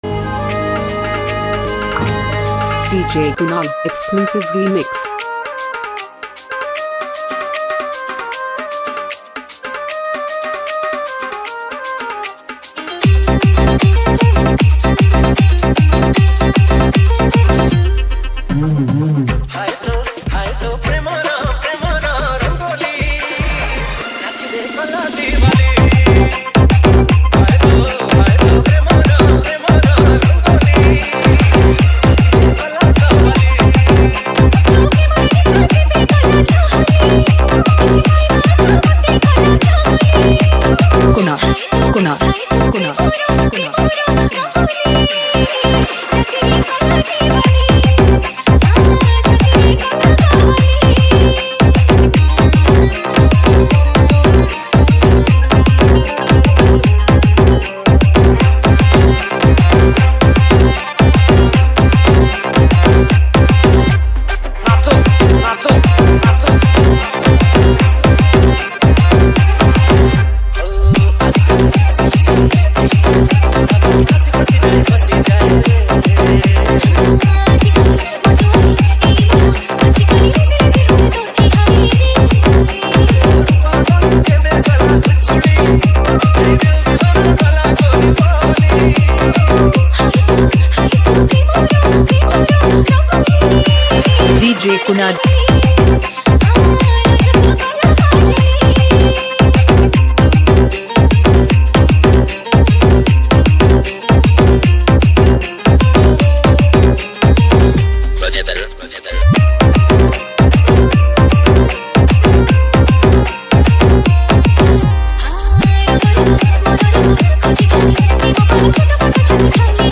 Category:  New Odia Dj Song 2023